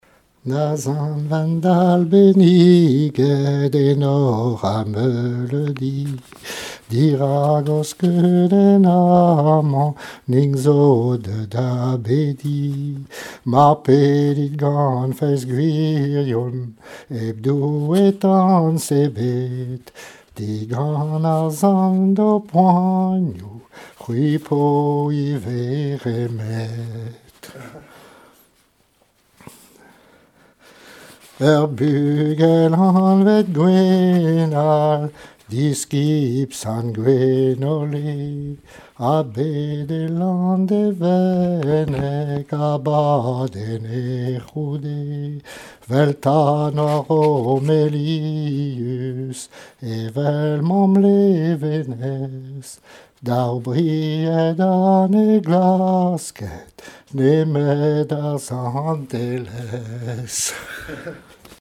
Douarnenez
Genre strophique
Cantiques et témoignages en breton
Pièce musicale inédite